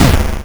ihob/Assets/Extensions/explosionsoundslite/sounds/bakuhatu74.wav at master
bakuhatu74.wav